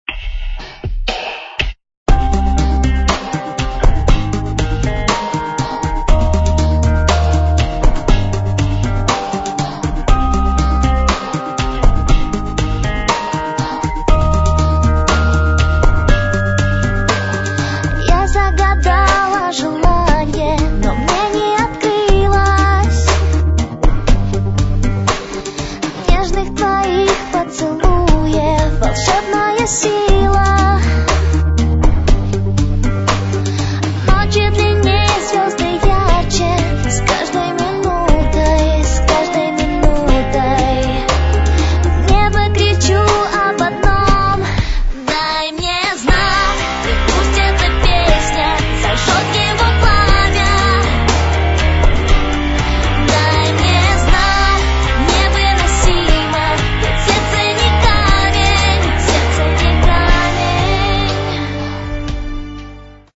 извените за качество